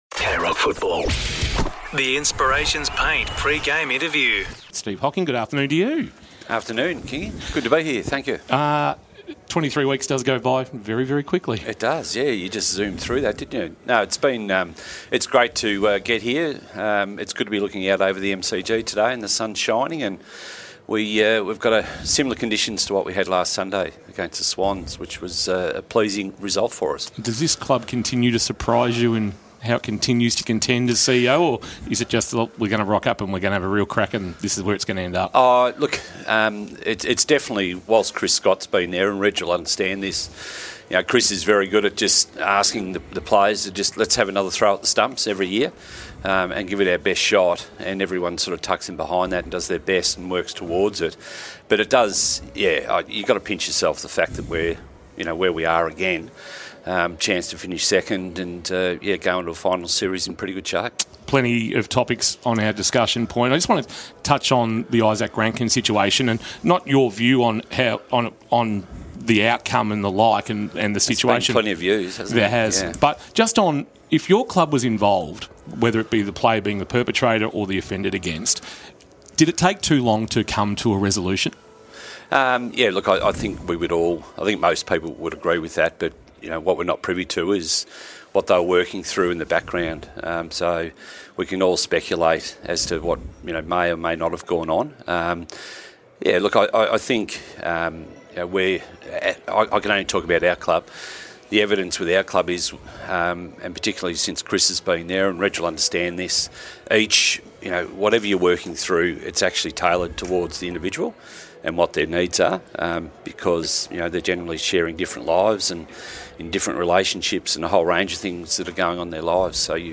2025 - AFL - Round 24 - Richmond vs. Geelong - Pre-match interview